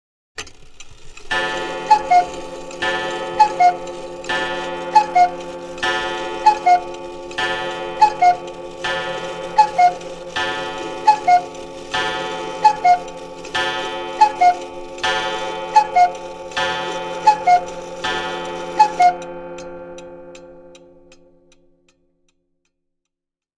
Cuckoo Clock Sound Effect
Mechanical cuckoo wall clock chimes at midnight sound effect.
Cuckoo-clock-sound-effect.mp3